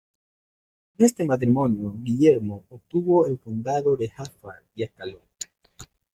Pronounced as (IPA) /ɡiˈʝeɾmo/